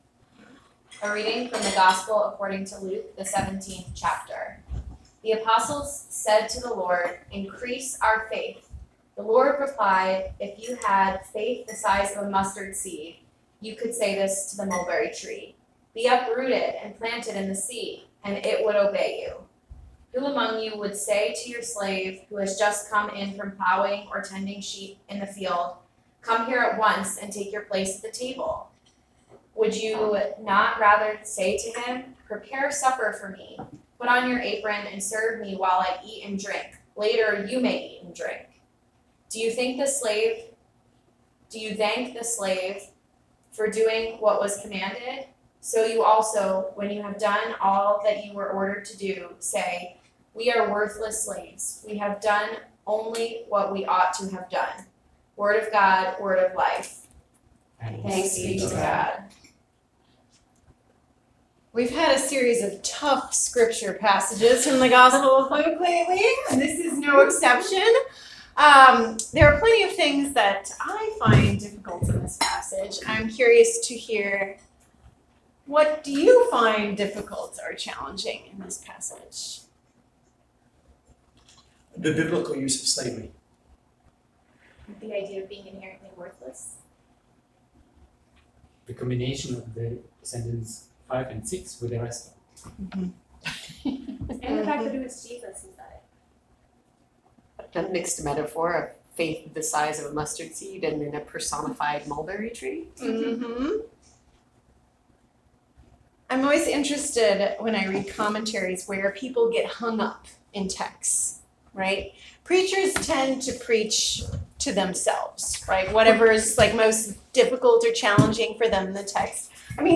October 7, 2019 Sermon